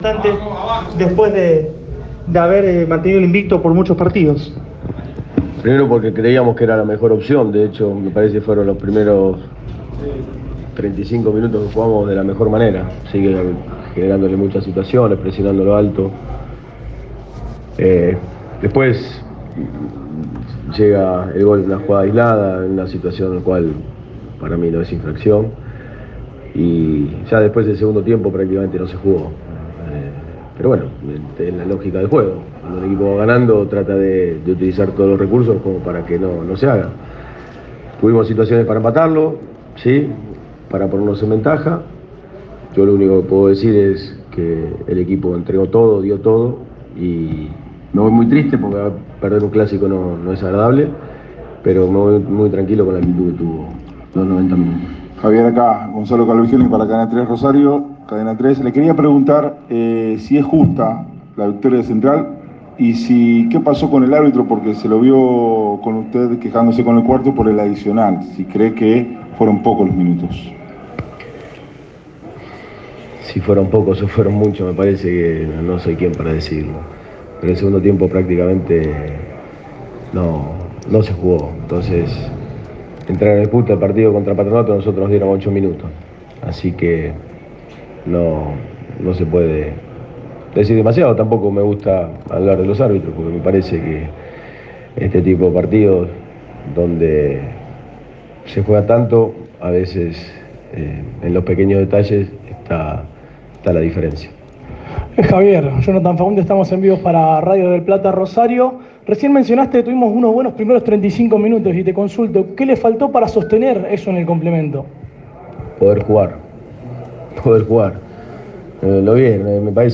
Luego de la derrota ante Rosario Central en el clásico por 1 a 0, el entrenador de Newell’s, Javier Sanguinetti dialogó en conferencia de prensa. el técnico sostuvo que los jugadores dieron todo y que a veces los pequeños detalles hacen la diferencia.